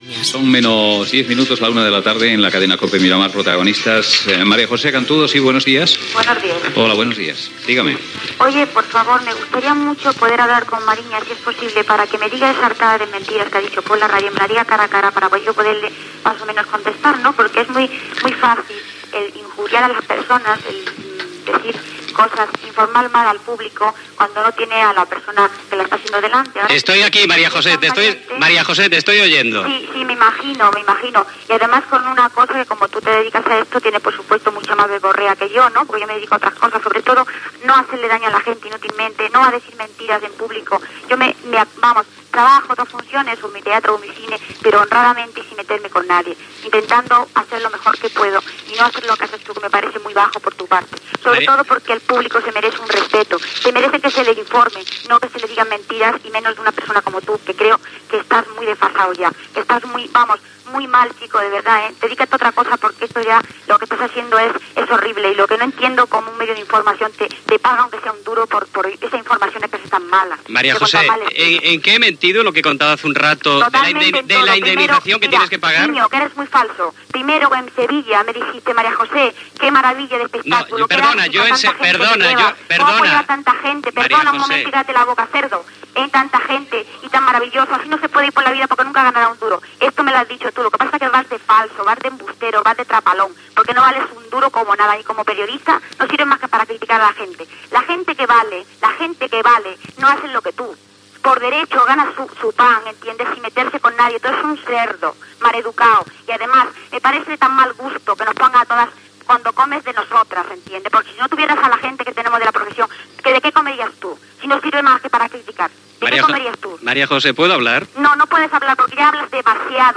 Hora, identificació del programa, de la cadena i de l'emissora, trucada de l'actriu María José Cantudo que expressa la seva queixa a la manera d'informar que té el periodista Luis Mariñas
Info-entreteniment